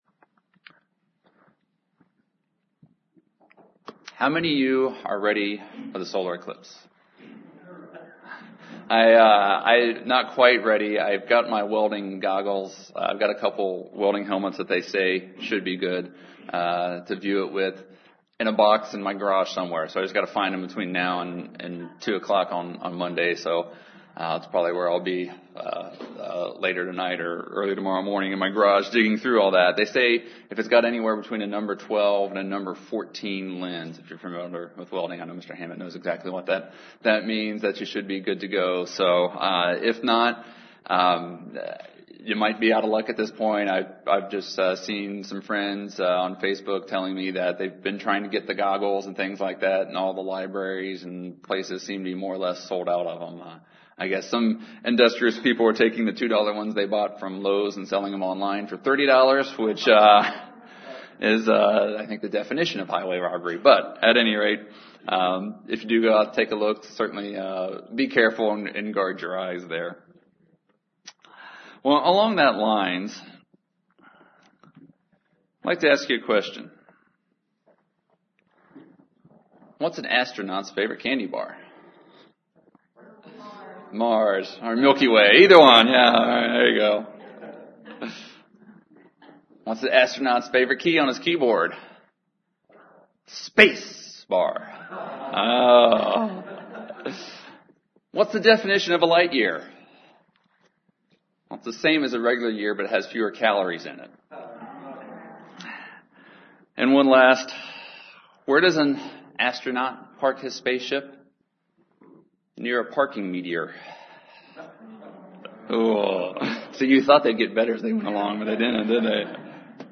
Given in Charlotte, NC Columbia, SC Hickory, NC